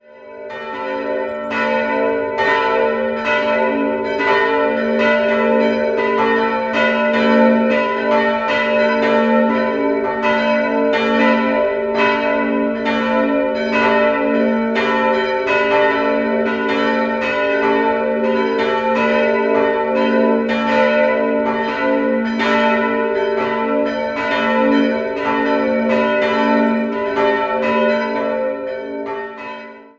Direkt neben der uralten Schäferkapelle (vermutlich an Stelle einer germanischen Kultstätte) erhebt sich die um 1200 errichtete Wehrkirche St. Michael. 3-stimmiges Geläut: a'-h'-fis'' Die kleine Glocke ist unbezeichnet und wurde im 16. Jahrhundert gegossen, die mittlere stammt von Andreas Philipp Stumm (Nürnberg) aus dem Jahr 1790 und die große entstand im Jahr 1610 bei Christoph Glockengießer (Nürnberg).